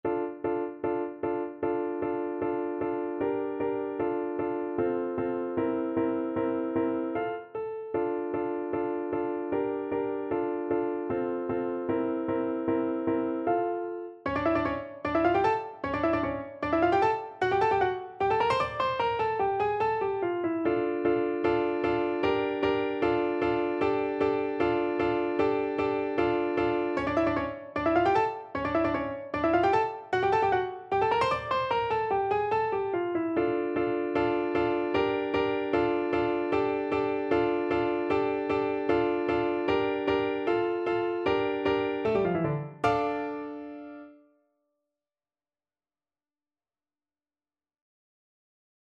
Play (or use space bar on your keyboard) Pause Music Playalong - Piano Accompaniment Playalong Band Accompaniment not yet available reset tempo print settings full screen
D minor (Sounding Pitch) A minor (French Horn in F) (View more D minor Music for French Horn )
2/4 (View more 2/4 Music)
Allegro scherzando (=152) (View more music marked Allegro)
Classical (View more Classical French Horn Music)